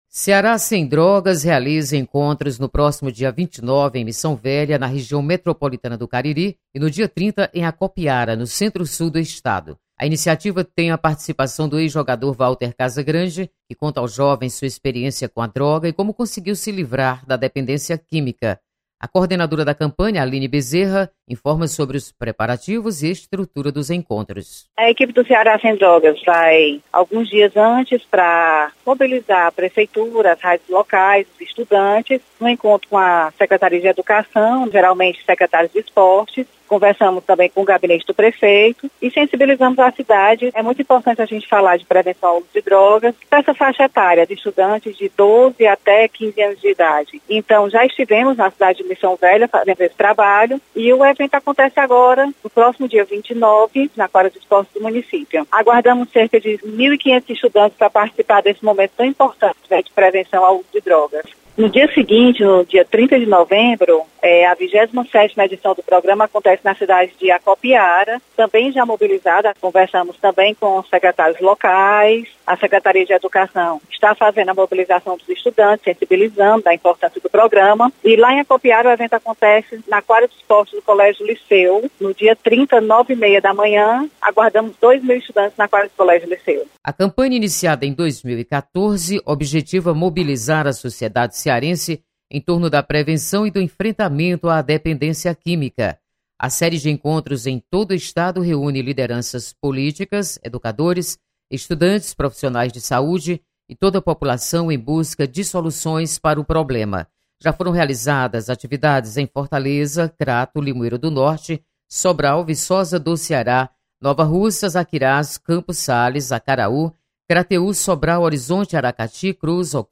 Você está aqui: Início Comunicação Rádio FM Assembleia Notícias Ceará sem Drogas